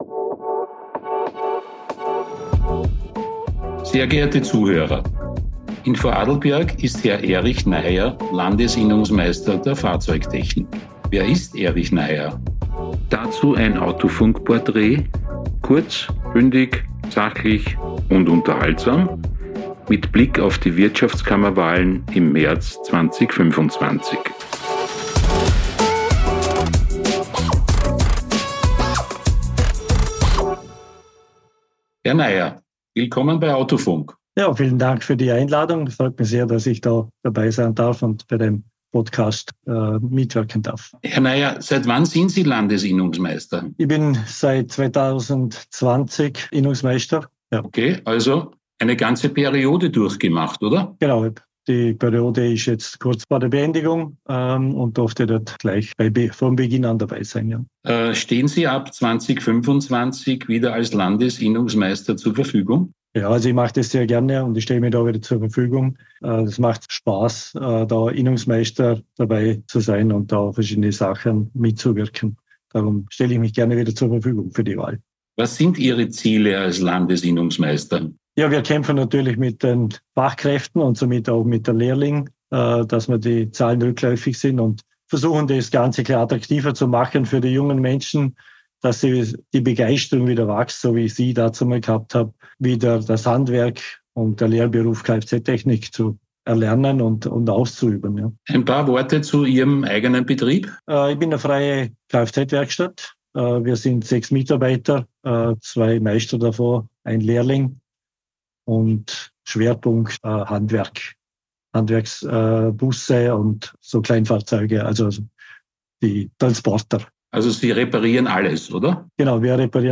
Kurz - Bündig - Sachlich - Unterhaltsam In der Sonderserie